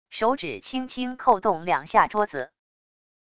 手指轻轻扣动两下桌子wav音频